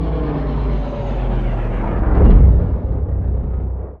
liftstop.ogg